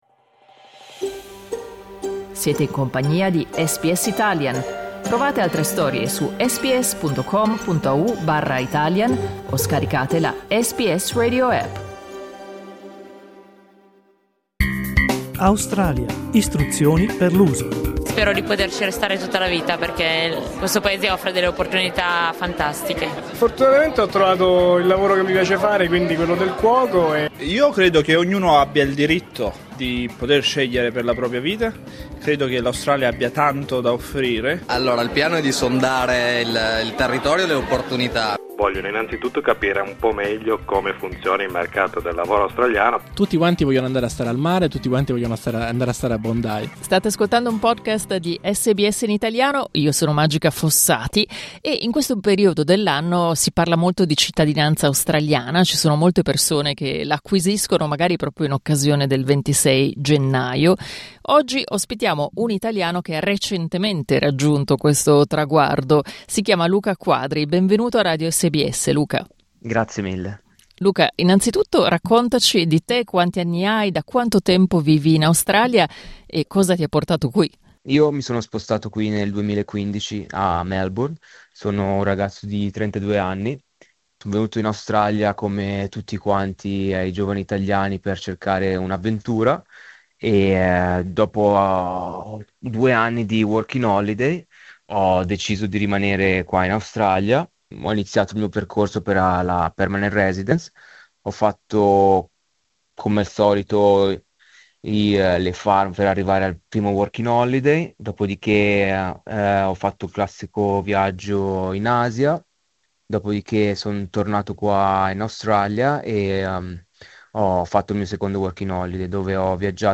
Clicca sul tasto "play" in alto per ascoltare l'intervista Prima di venire in Australia